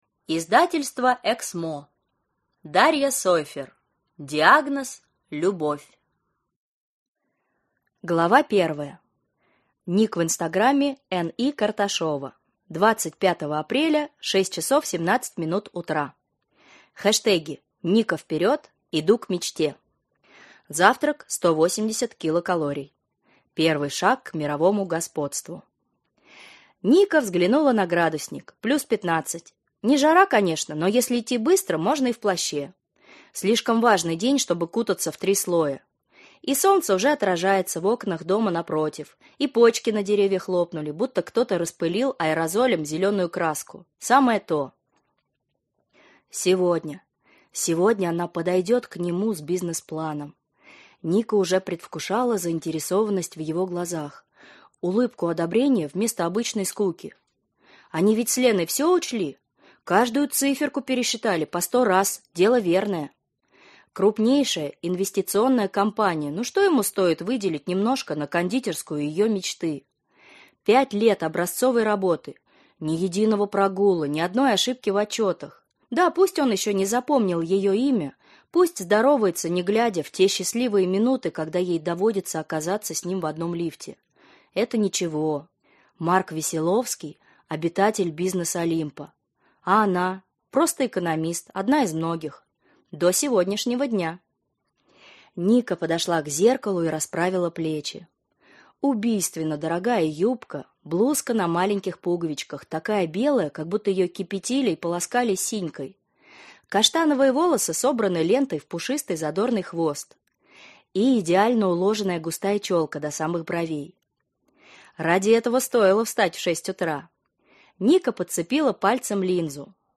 Аудиокнига Диагноз: любовь | Библиотека аудиокниг